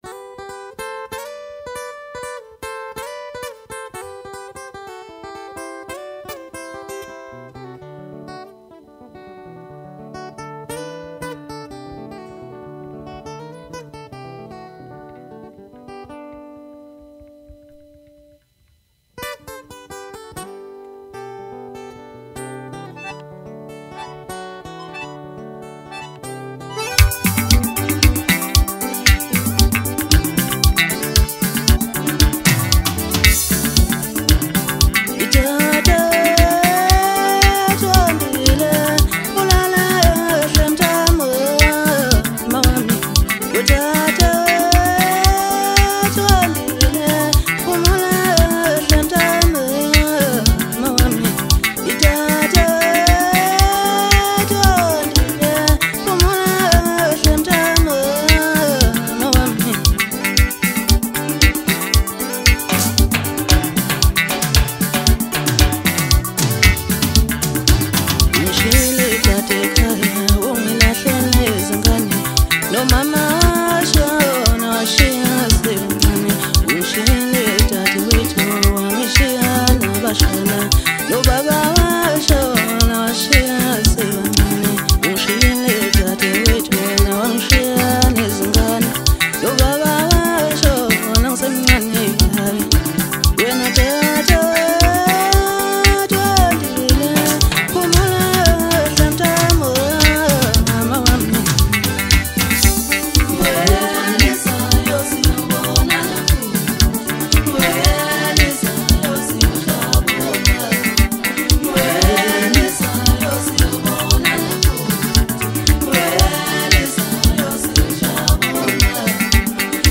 Home » Maskandi Music » Maskandi